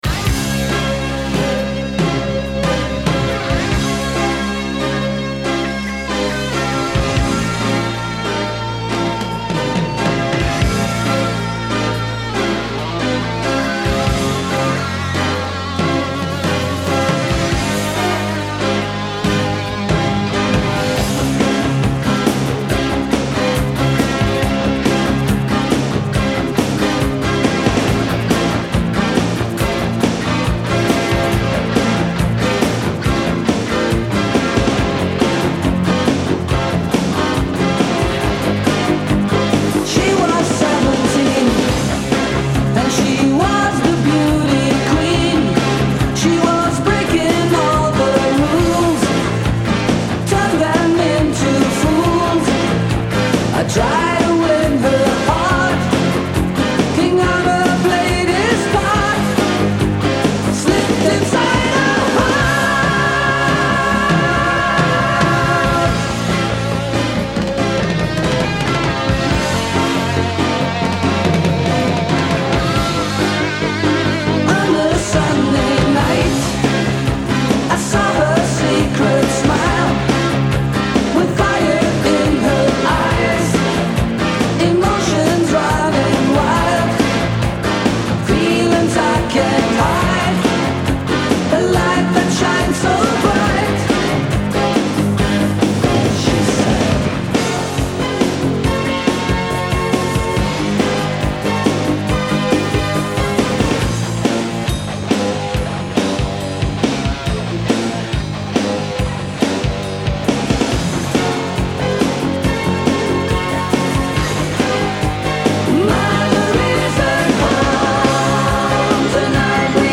lead guitar
drums
bass